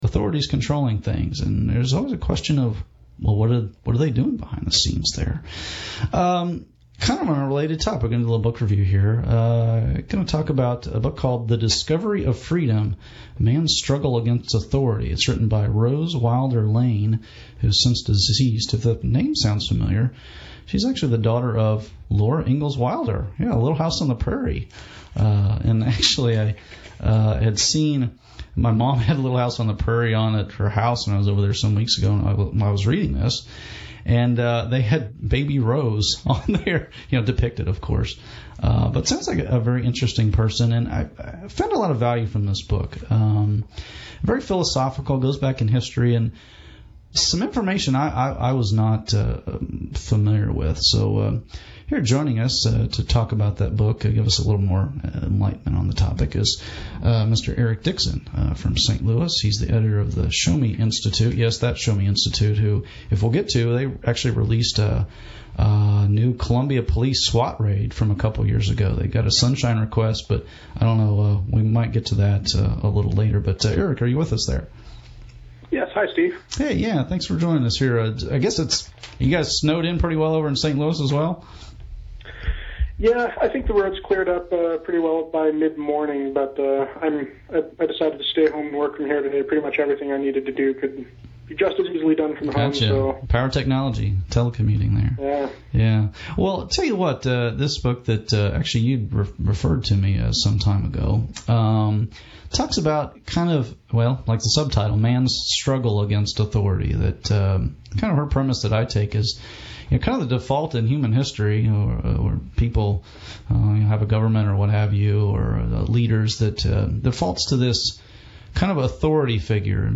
Interview: The Discovery of Freedom